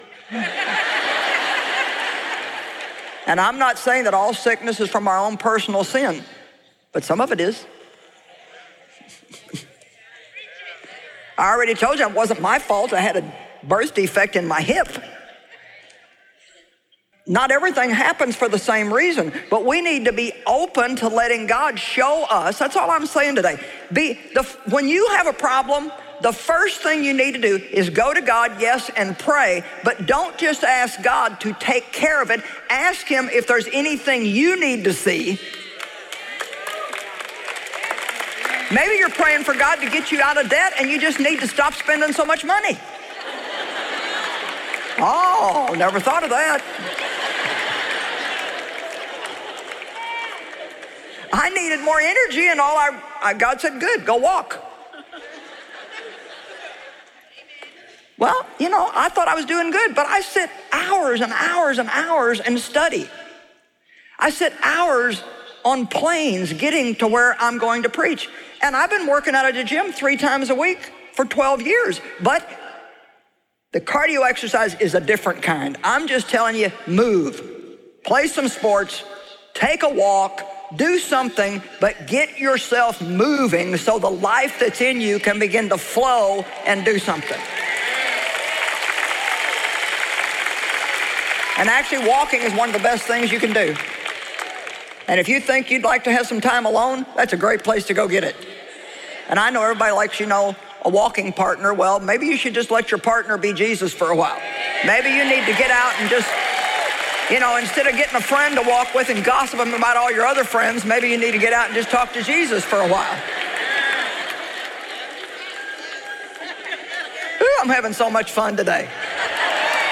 Healthy Living: Spirit, Soul and Body Audiobook
Narrator
Joyce Meyer
4.10 Hrs. – Unabridged